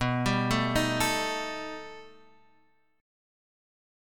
B+7 chord